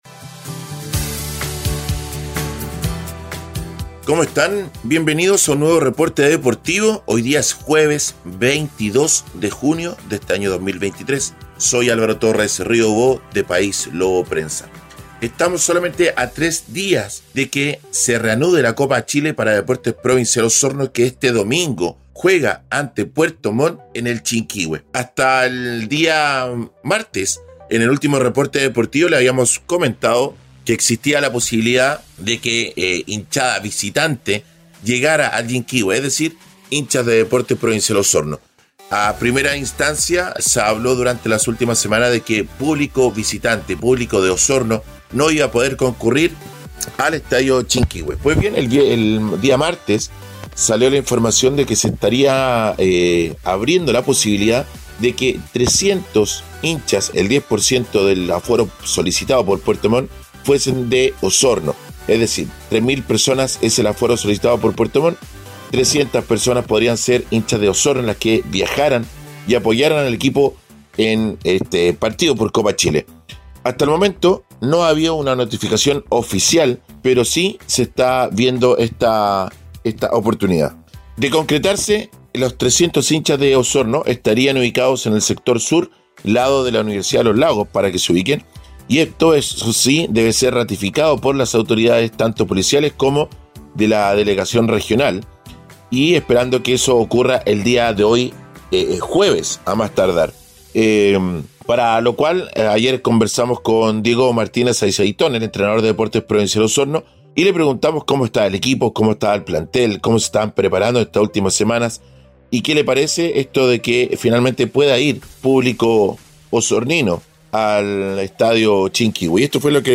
Reporte Deportivo 🎙 Podcast 22 de junio de 2023